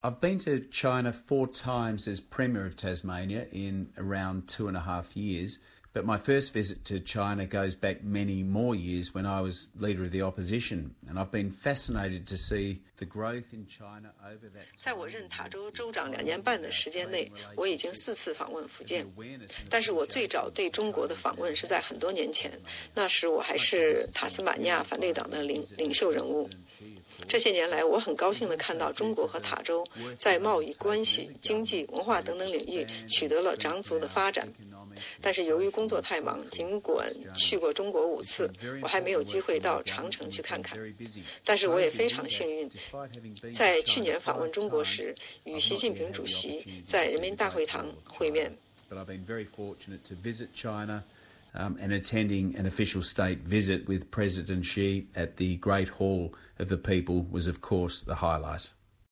塔州州长Will Hodgman说，他担任州长以来，多次访华: